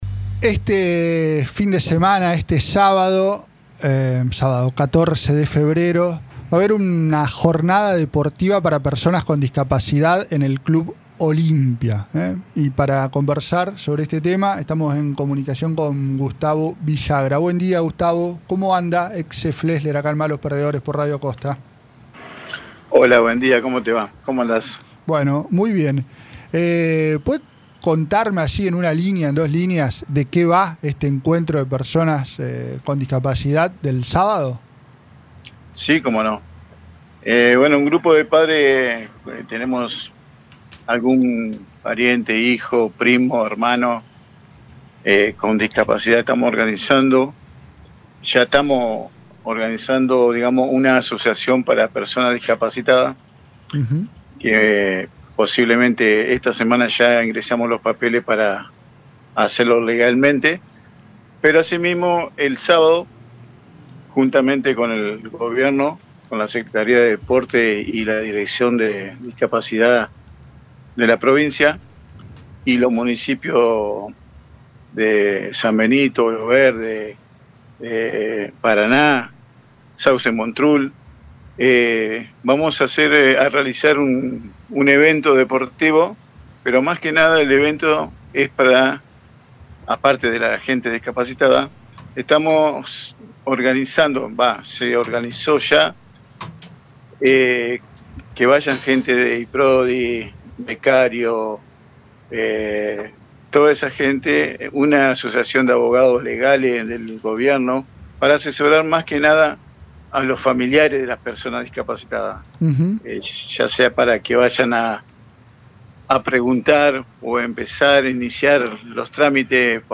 durante una entrevista con Malos Perdedores